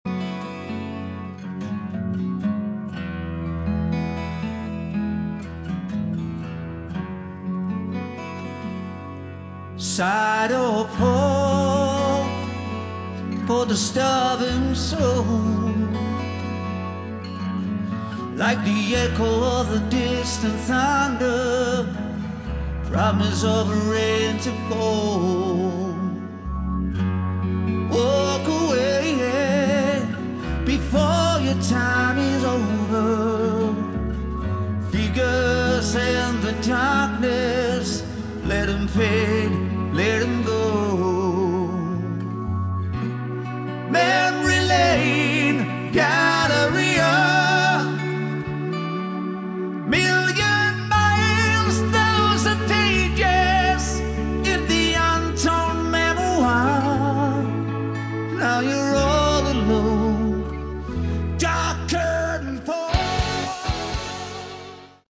Category: Melodic Metal
bass
keyboards
vocals
guitar
drums